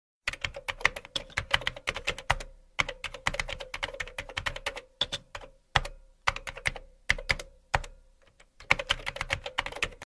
Keyboard Typing Effect